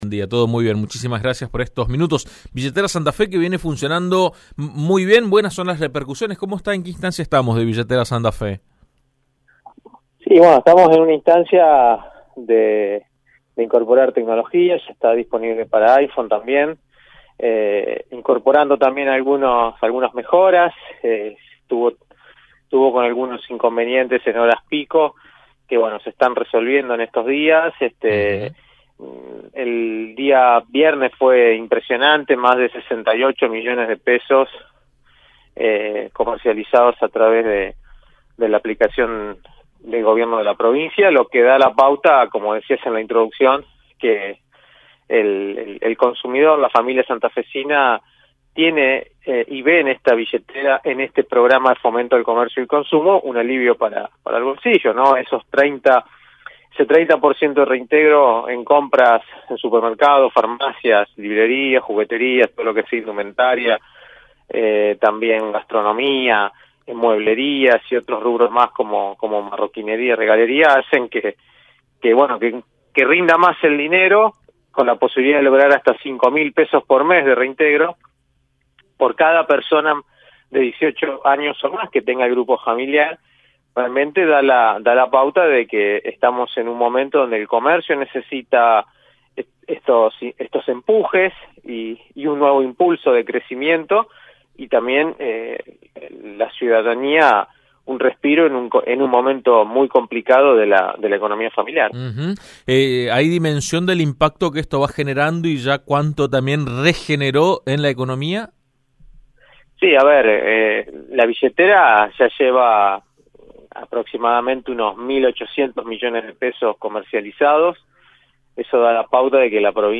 AM 1330 dialogó con el secretario de comercio interior Juan Marcos Aviano acerca del aumento de los precios de los últimos meses y del éxito que está teniendo la utilización de la aplicación “Billetera Santa Fe” para acceder a descuentos financiados por el gobierno provincial.